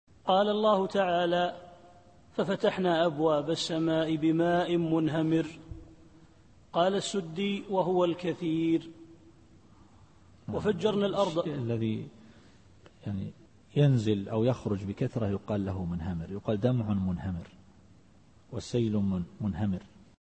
التفسير الصوتي [القمر / 11]